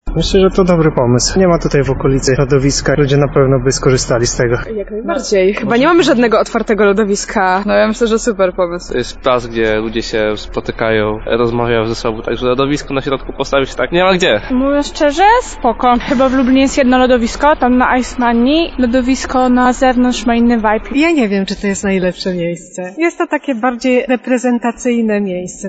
Sonda lodowisko